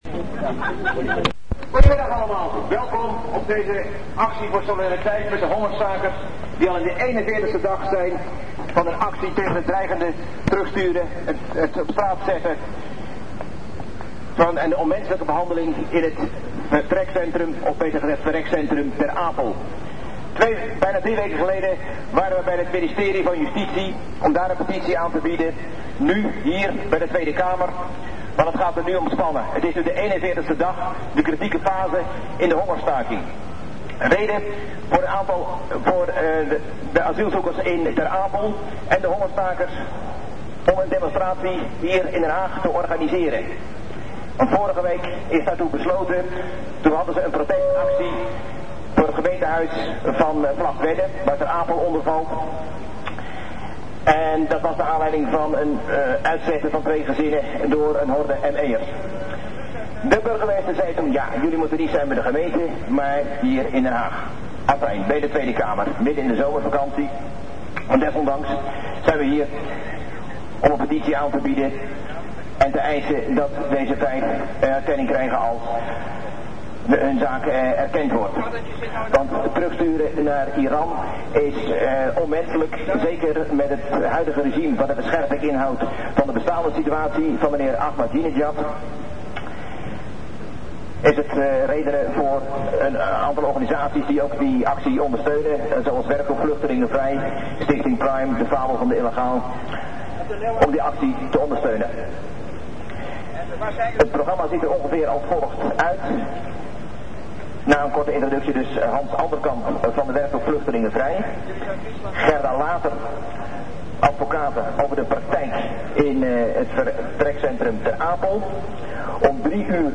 Afgelopen woensdag was er in Den Haag een protestmanifestatie tegen de behandeling van vluchtelingen in de vertrekcentra Ter Apel en Vught. De manifestatie was tevens ter ondersteuning van de Iraanse hongerstakers.